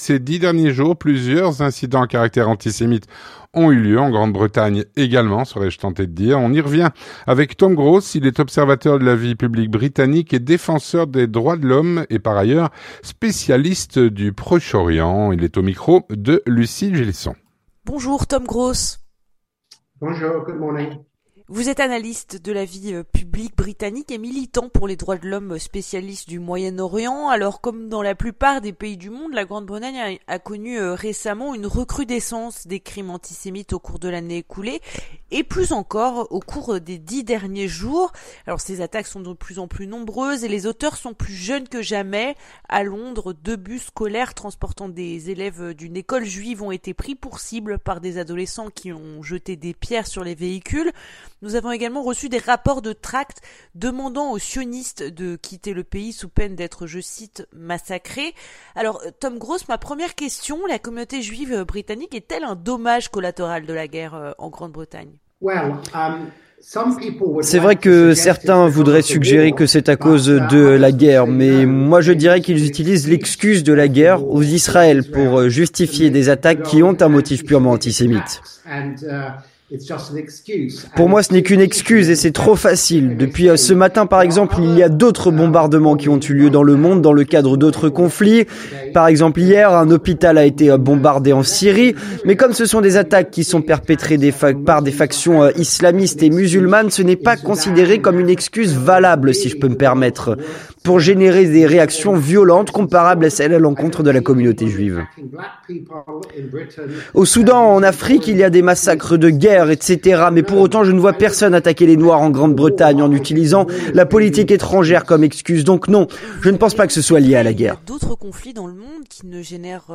L'entretien du 18H - Ces derniers jours, plusieurs incidents antisémites ont eu lieu en Grande Bretagne.